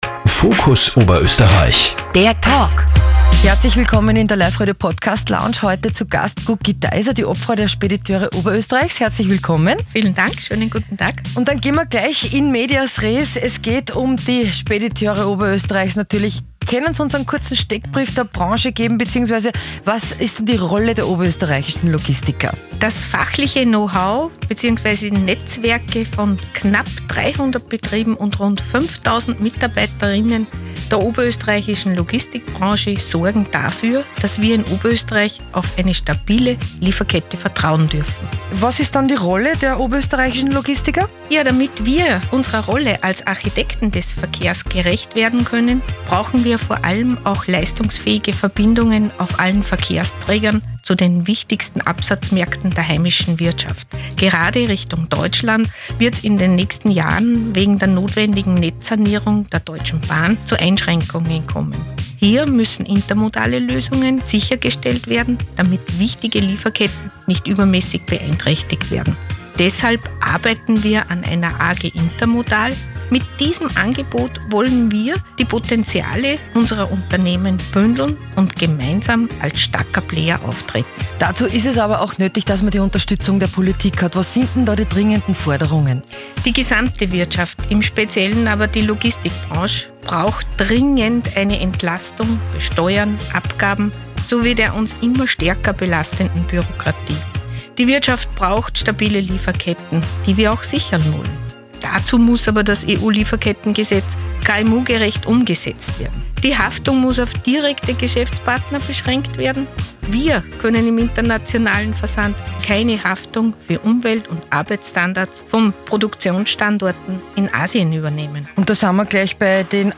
Radiospot